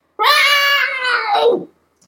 cat.ogg.mp3